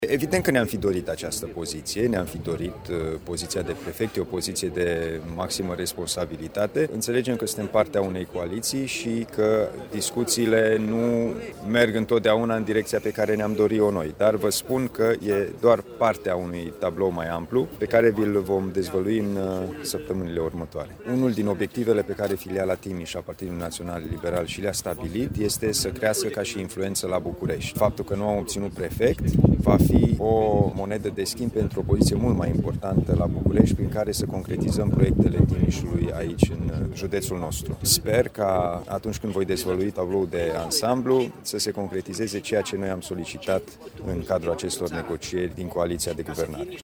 Pentru liberali, pierderea acestei poziții trebuie văzută în altă cheie, spune președintele PNL Timiș, Alin Nica.